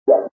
SButtonClick.ogg